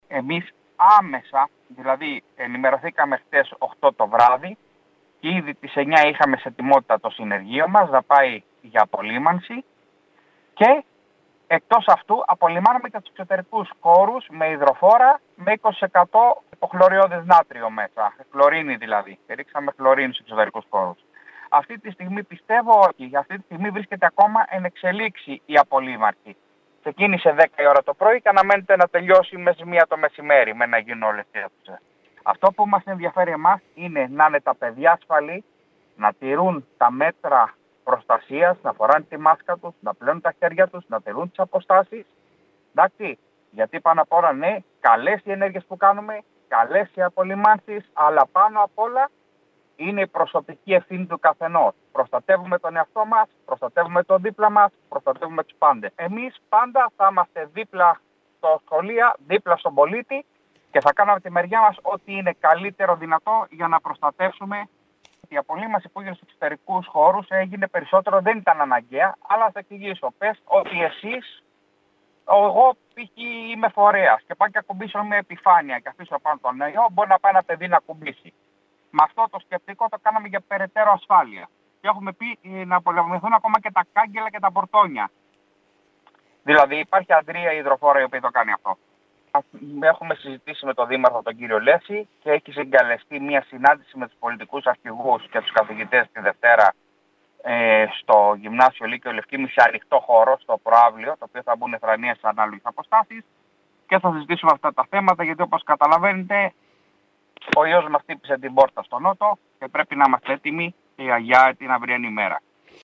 Σύμφωνα με τον αντιδήμαρχο Παιδείας, Γιώργο Σαγιά, ο δήμαρχος Νότιας Κέρκυρας, Κώστας Λέσσης, έχει ήδη συγκαλέσει σύσκεψη φορέων και των επικεφαλής των παρατάξεων του Δημοτικού συμβουλίου τη Δευτέρα στις 11.00 το πρωί, προκειμένου να συζητήσουν τα επόμενα βήματα σε επίπεδο Δήμου ώστε να αποφευχθεί εξάπλωση της επιδημίας.